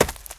STEPS Leaves, Run 17.wav